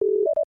st-terminal-beep.wav